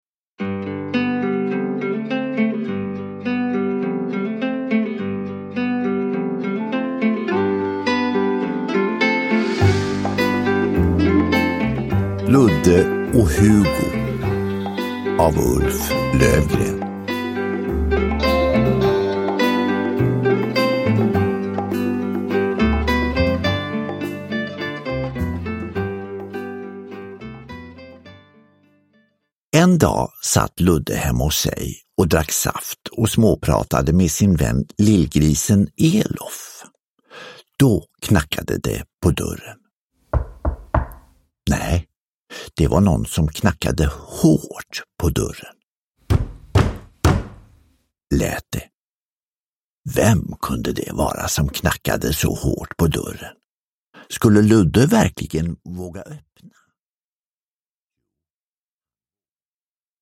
Ludde och Hugo – Ljudbok – Laddas ner